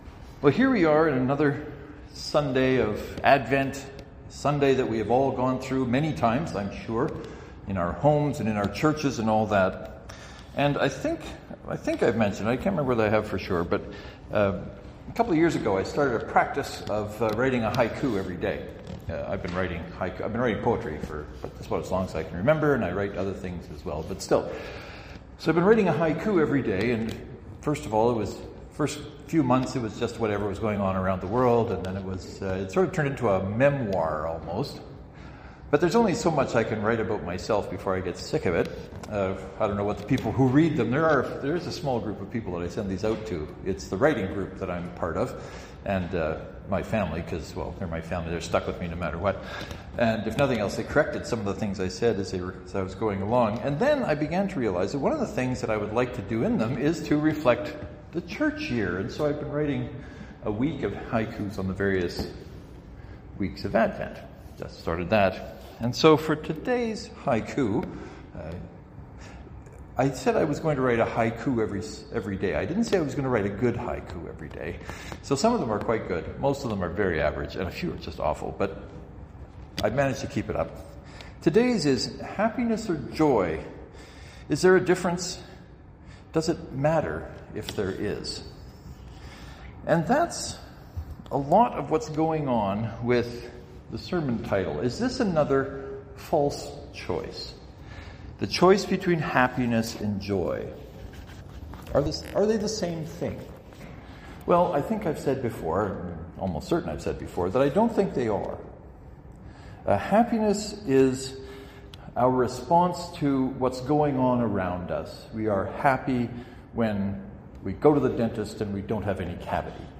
The sermon goes into more detail than this brief sketch.